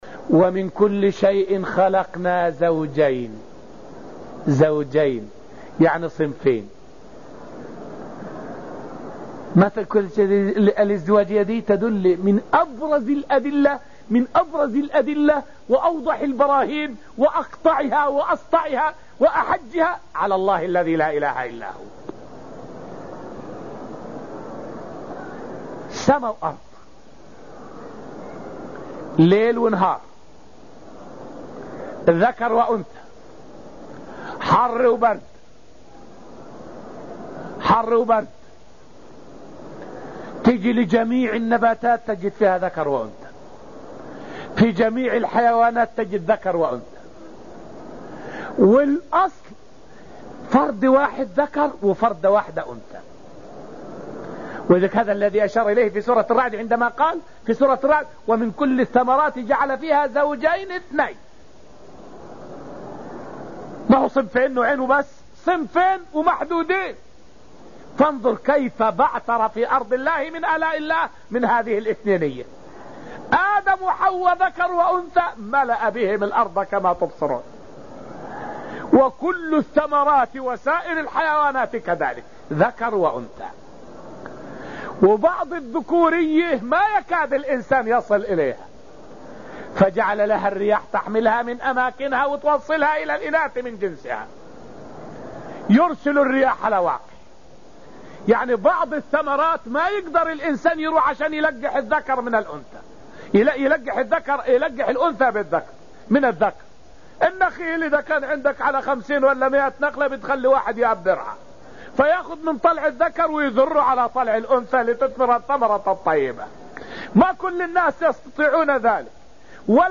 فائدة من الدرس السادس من دروس تفسير سورة الذاريات والتي ألقيت في المسجد النبوي الشريف حول معنى: {من كل شيء خلقنا زوجين} ودلالتها على قدرة الله.